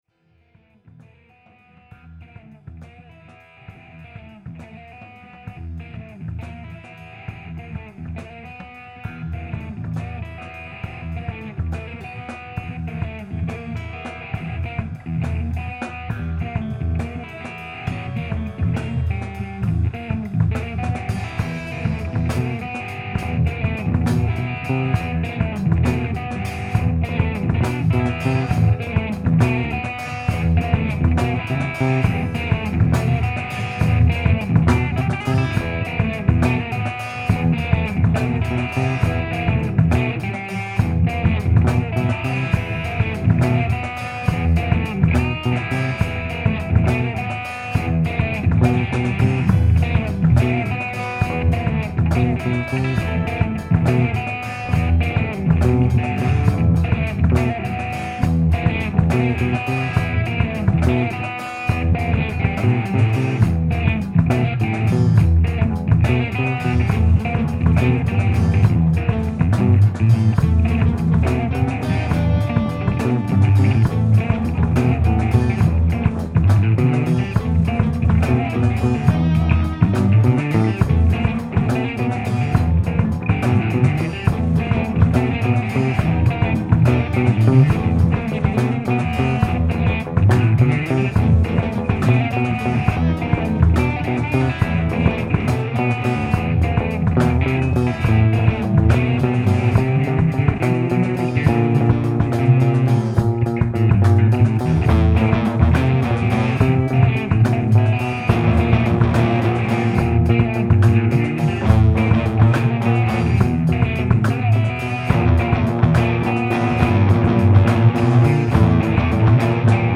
bass, keyboards, vocals
drums
guitar